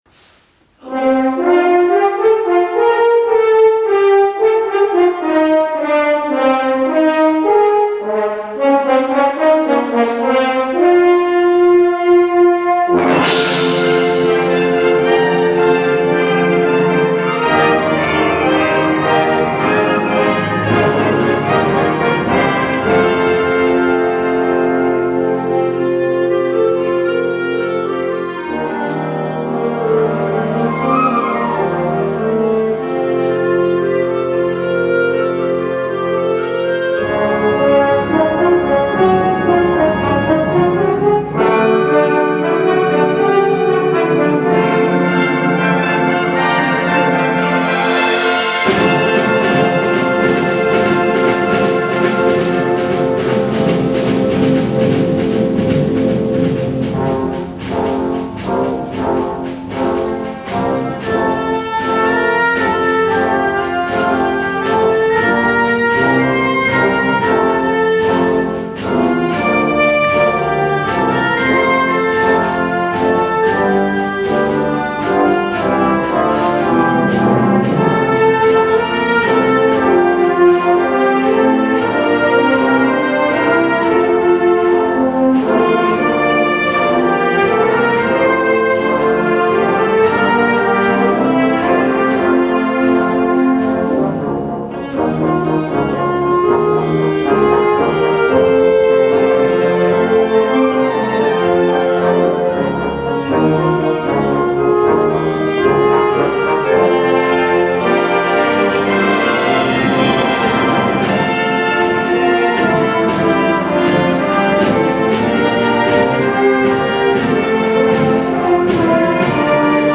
2012年9月30 オータムコンサート